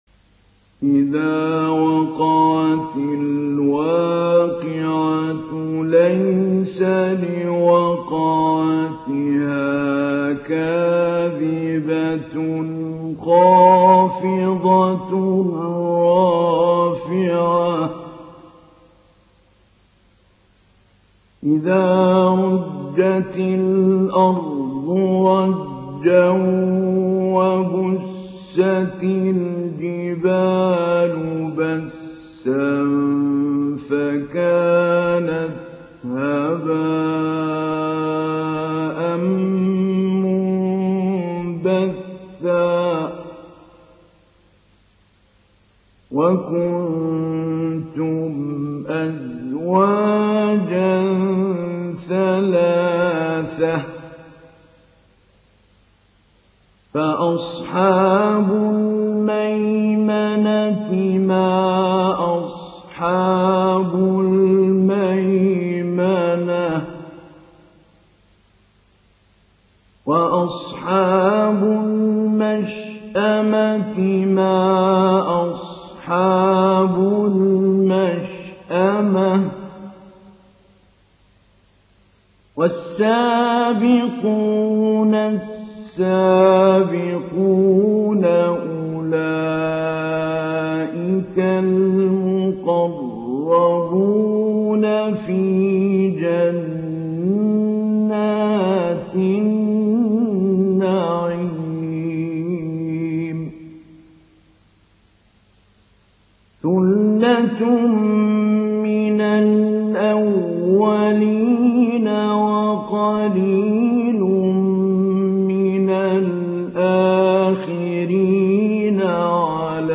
İndir Vakia Suresi Mahmoud Ali Albanna Mujawwad
Mujawwad